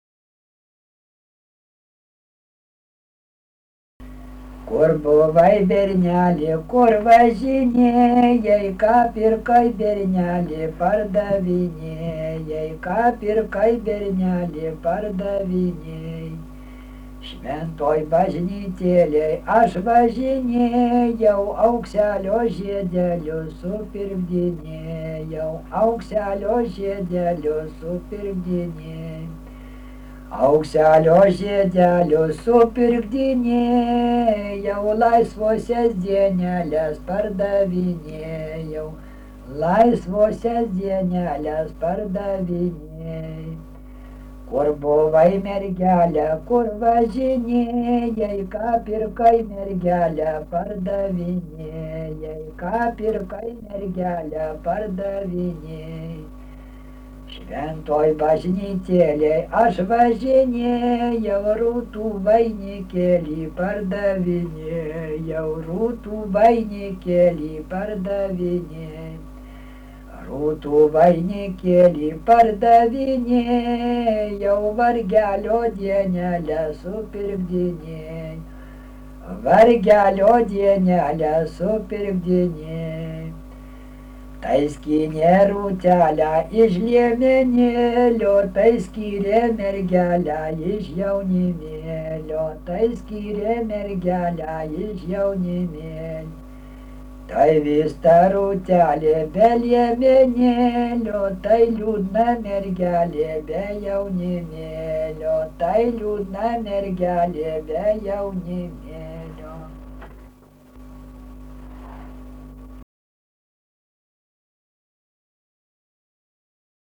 Subject daina
Erdvinė aprėptis Mikalavas
Atlikimo pubūdis vokalinis